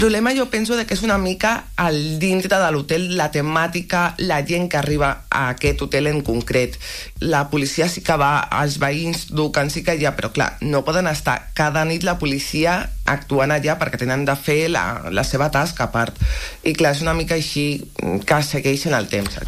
L’any d’activitat de la Sindicatura Municipal va de juliol a juliol, i a l’espera del balanç oficial de l’últim i en seu plenària, Natàlia Costa n’ha donat detalls en una entrevista a l’FM i + de Ràdio Calella TV.